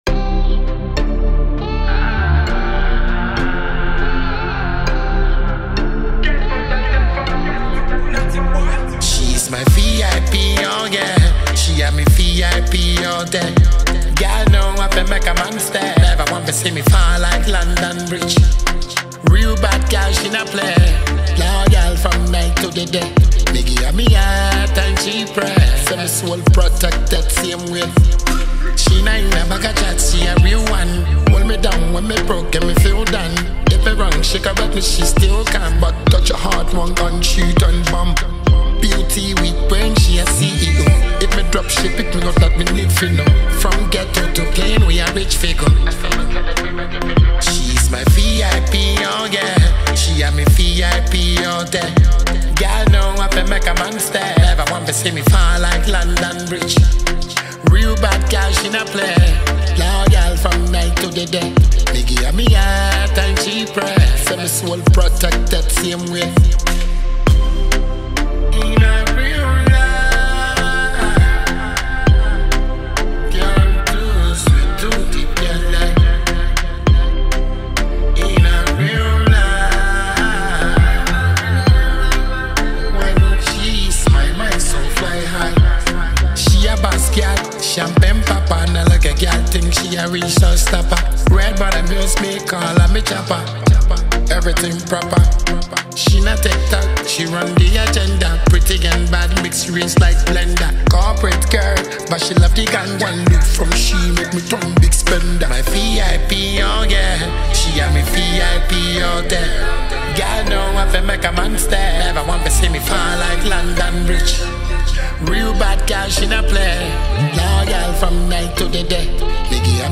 a smooth, infectious vibe